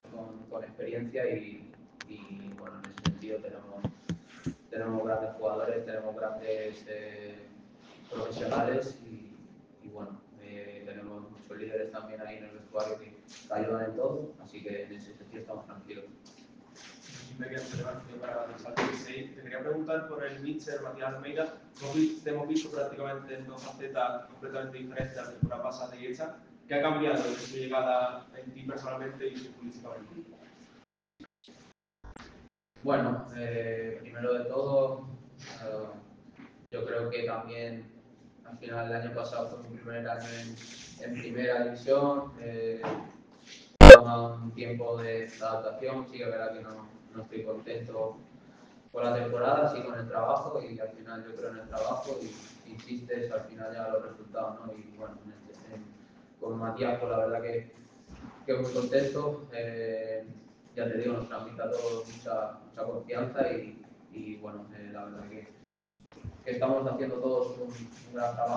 Gerard Fernández «Peque» volvió a situarse en el foco mediático tras comparecer en la sala de prensa del Estadio Jesús Navas.